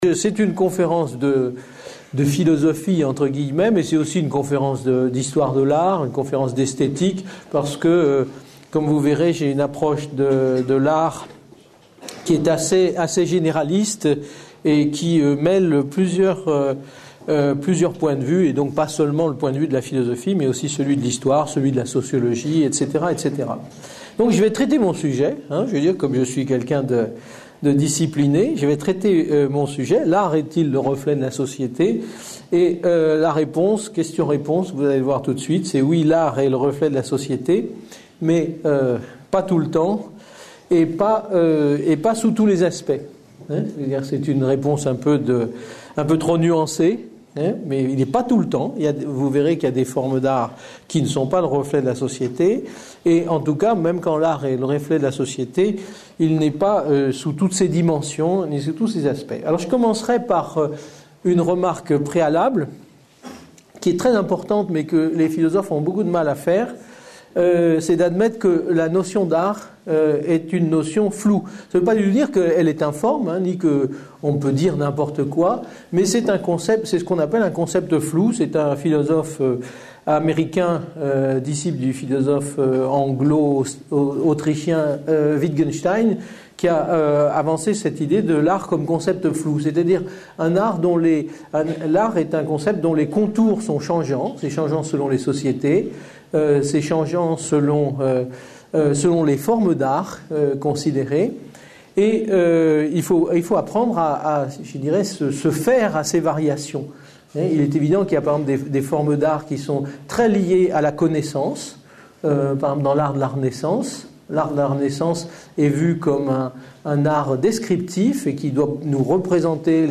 Une conférence de l'UTLS au lycée par Yves Michaud.